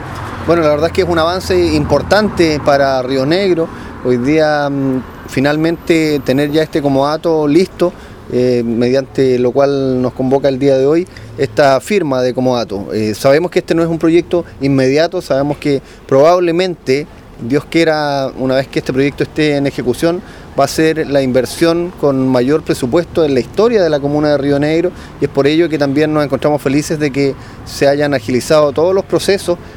AlcaldeRioNegro.mp3